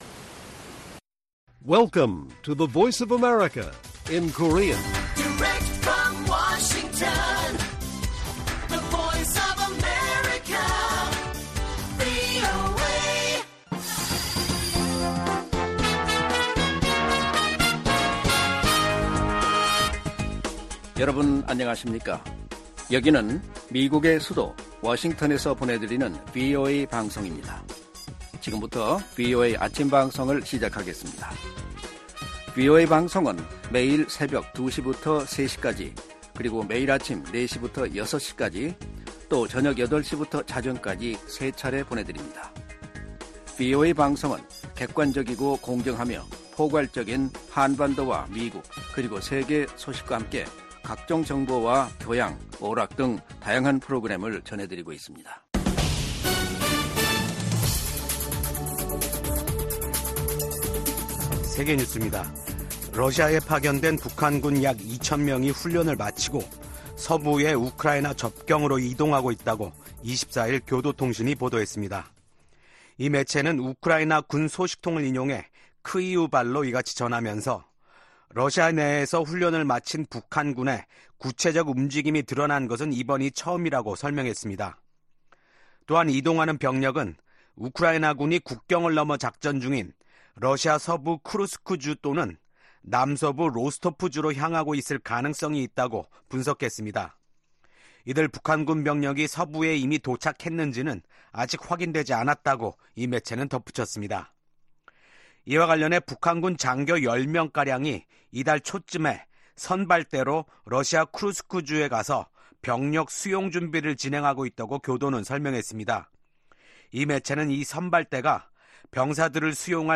세계 뉴스와 함께 미국의 모든 것을 소개하는 '생방송 여기는 워싱턴입니다', 2024년 10월 25일 아침 방송입니다. 이스라엘과 헤즈볼라가 싸우고 있는 레바논에 1억 800만 달러 규모 원조를 제공할 것이라고 에마뉘엘 마크롱 프랑스 대통령이 밝혔습니다. 미국 대선에서 지금까지 거의 2천 500만 명이 이미 투표한 것으로 집계됐습니다.